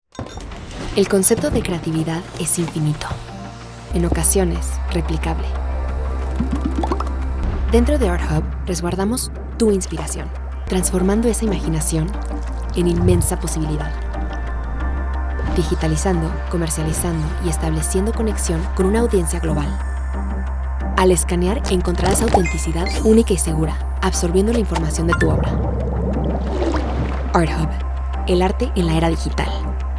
Spanish (Latin American)
Spanish (Mexican)
Commercial Demo
Home Studio, Rode NT1 5th Generation Condenser Mic